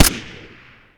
aug_shot_sil.ogg